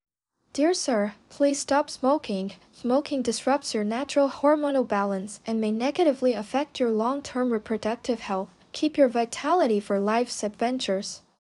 20-29 male.wav